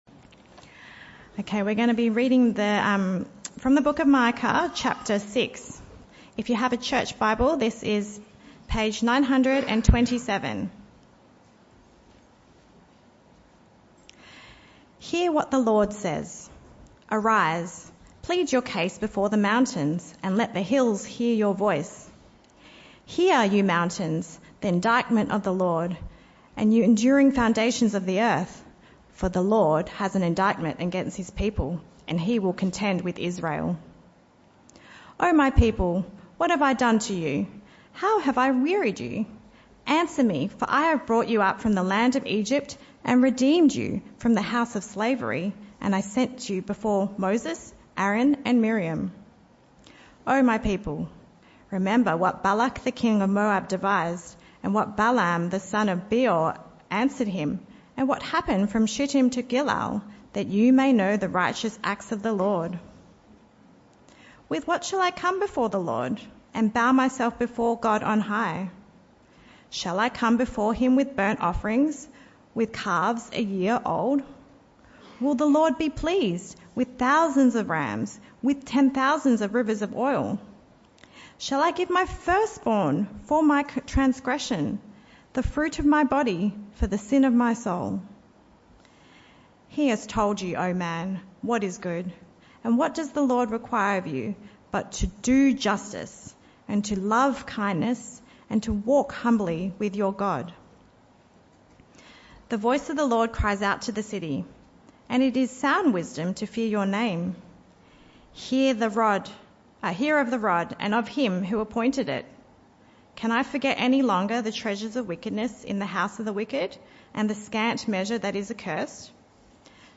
This talk was part of the AM Service series entitled Micah – Who Is Like Our God?